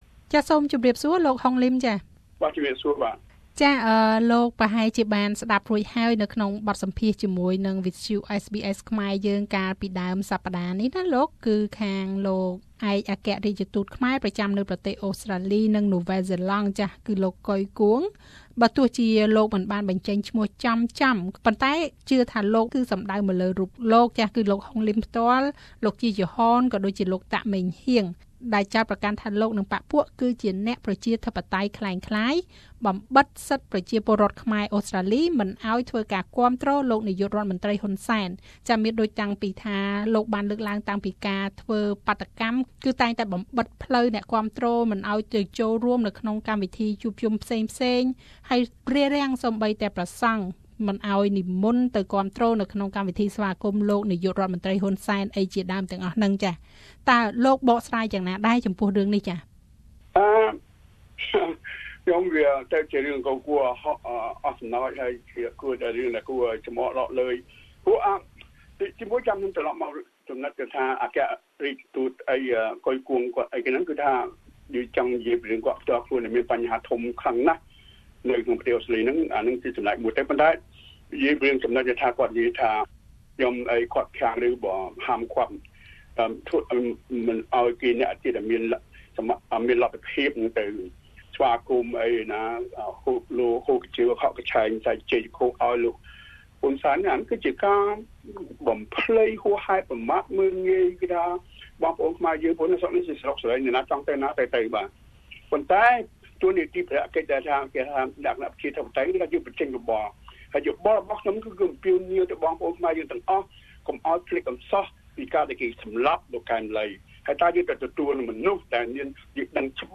ឆ្លើយតបចំពោះការចោទប្រកាន់នេះ លោក ហុង លីម សមាជិកសភារដ្ឋវិចថូរៀ បានផ្តល់នូវបទសម្ភាសន៍ដូចខាងក្រោម។